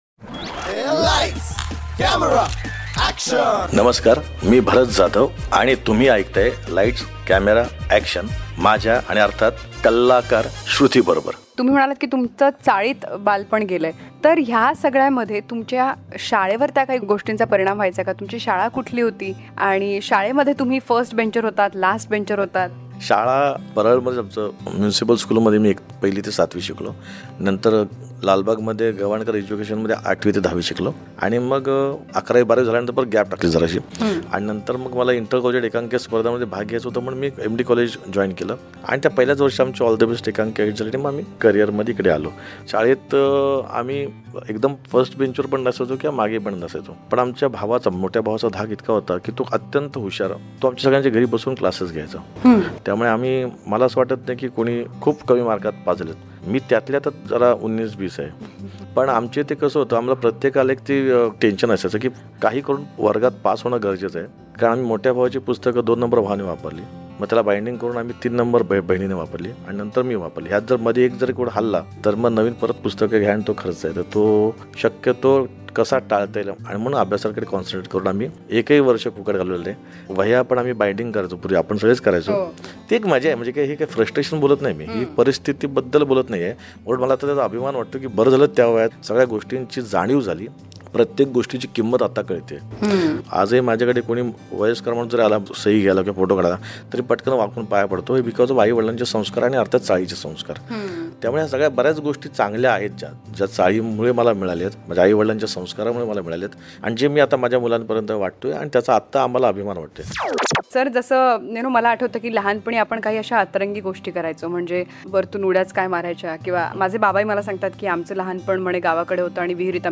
Listen to this podcast as the multifaceted actor Bharat Jadhav talks about his childhood, his bond with Kedar Jadhav & Ankush Chaudhary, his fond memories of legendary actor Late Dada Kondke, Late Laxmikant Berde and also about his family.